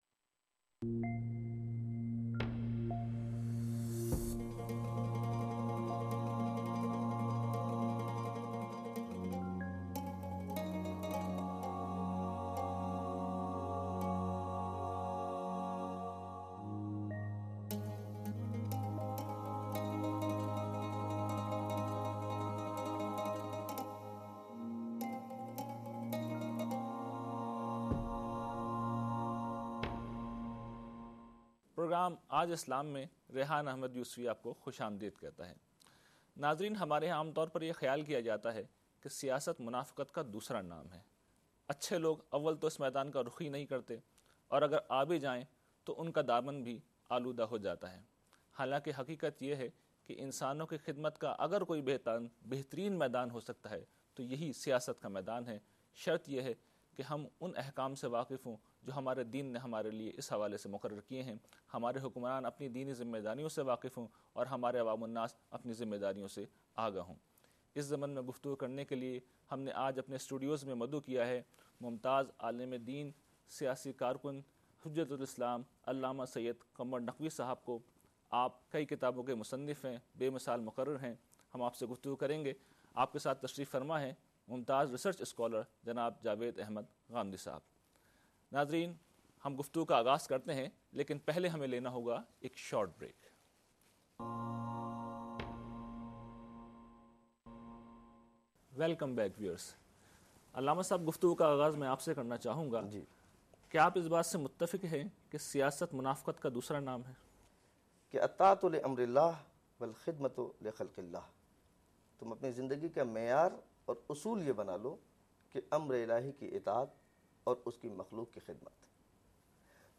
A thoughtful debate between Javed Ahmad Ghamidi and a shia scholar